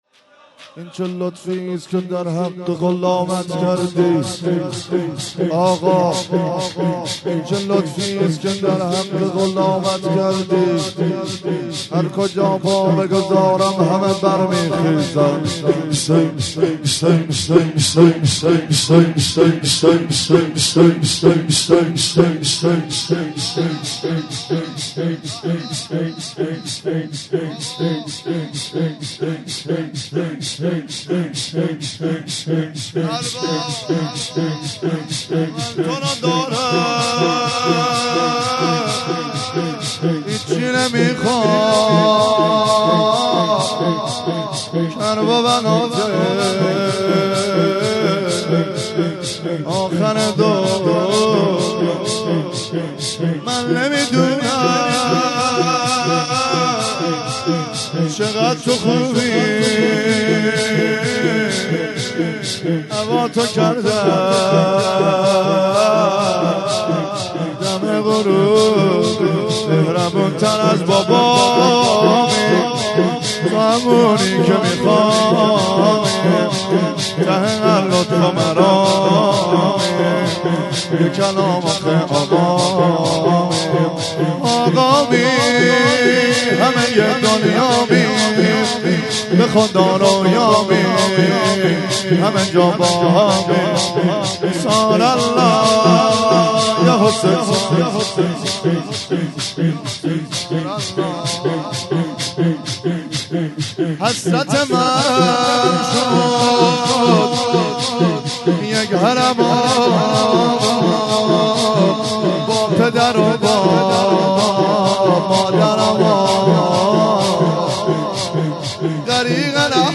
10- من تورو دارم هیچی نمیخوام - شور